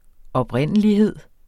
Udtale [ ʌbˈʁεnˀəliˌheðˀ ]